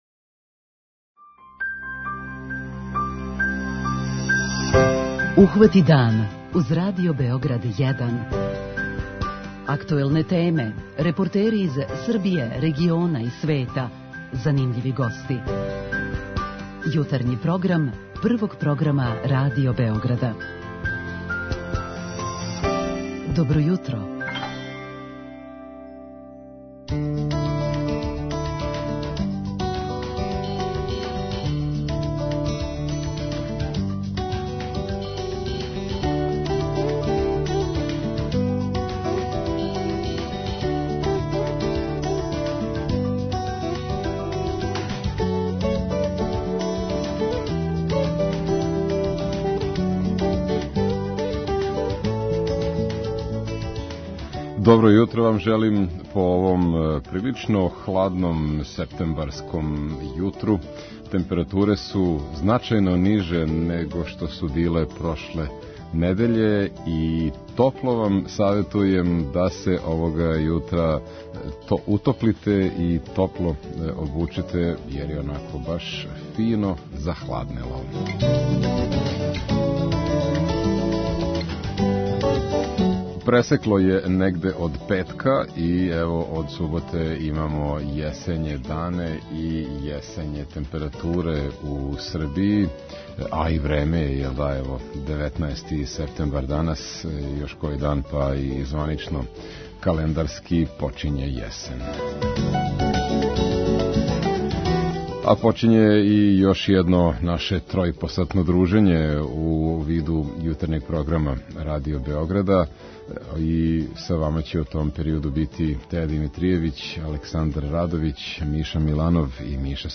У овом издању јутарњег програма имаћемо и троје гостију у студију.